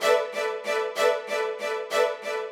GS_Viols_95-G1.wav